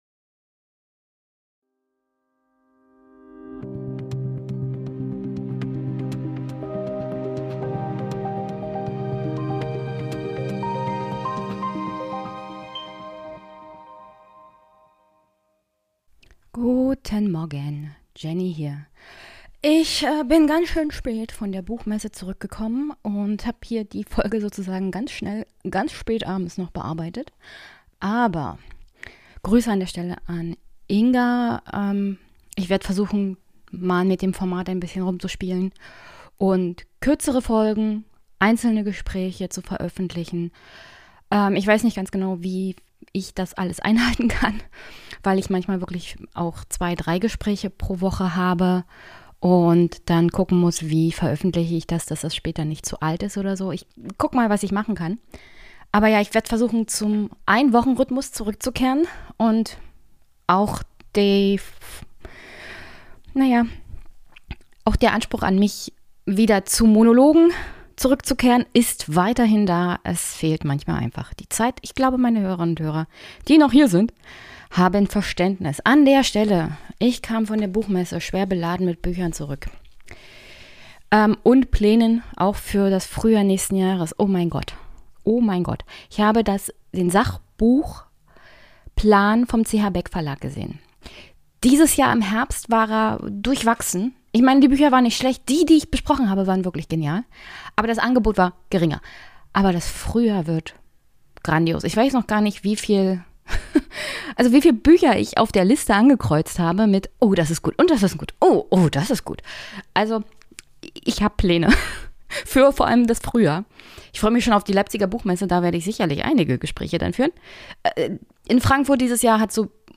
Dem falschen politischen Spiel mit gesellschaftlichen Ängsten. Das Gespräch entstand auf der Frankfurter Buchmesse.